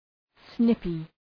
{‘snıpı}